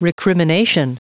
Prononciation du mot recrimination en anglais (fichier audio)
Prononciation du mot : recrimination